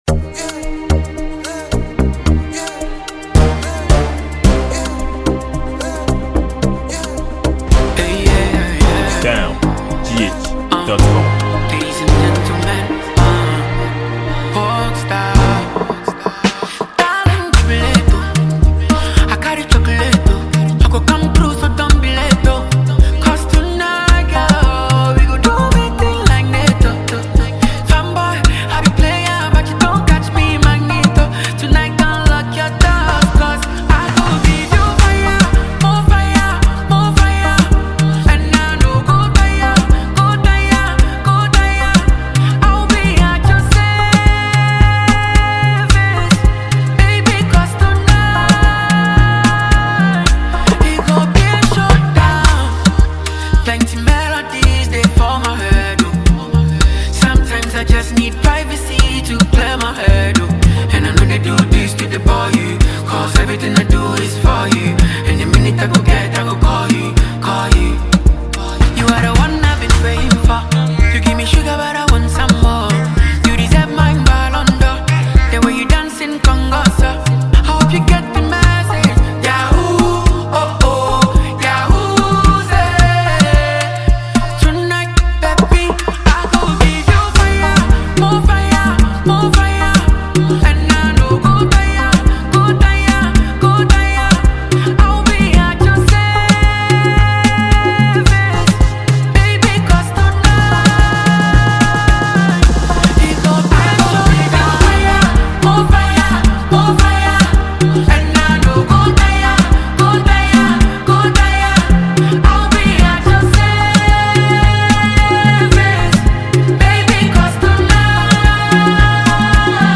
a Ghanaian highlife singer and award-winning musician
highlife love banger